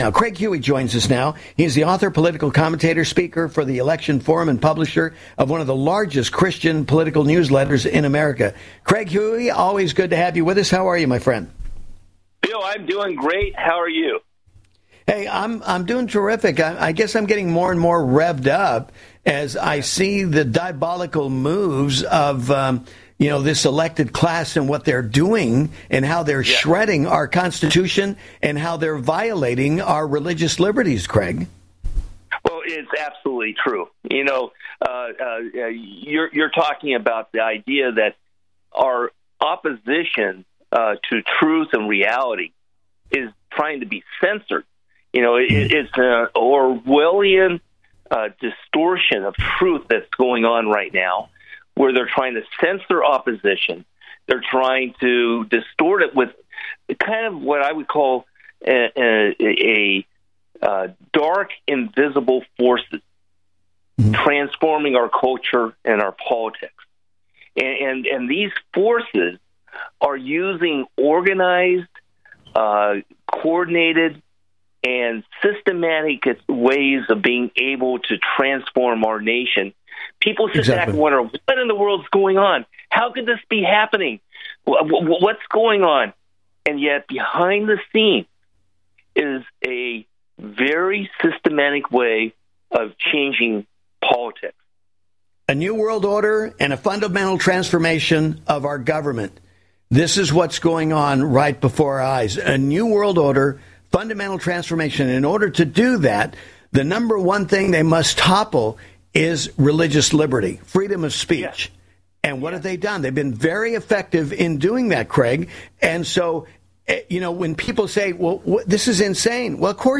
The interview is about 25 minutes long.